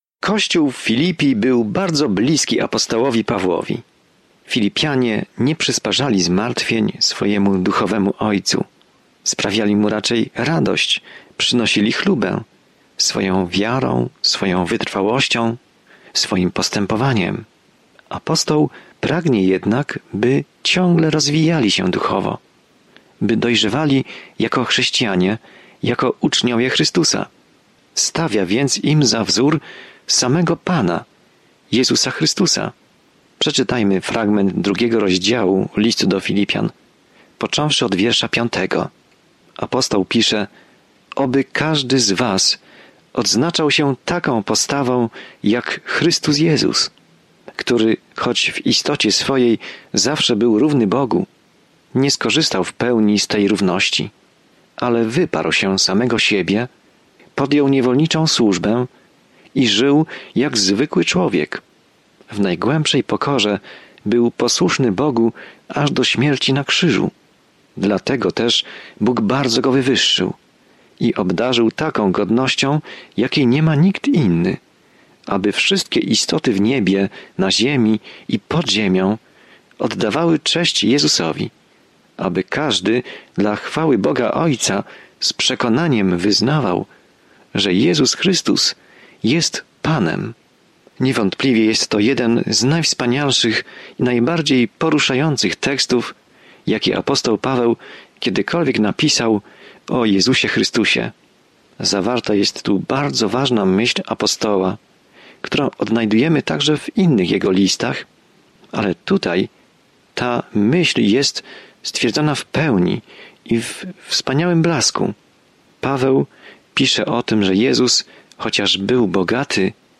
Pismo Święte Filipian 2:5-11 Dzień 5 Rozpocznij ten plan Dzień 7 O tym planie To podziękowanie skierowane do Filipian daje im radosną perspektywę na trudne czasy, w których się znajdują, i zachęca ich, aby pokornie przez nie przejść razem. Codziennie podróżuj przez List do Filipian, słuchając studium audio i czytając wybrane wersety słowa Bożego.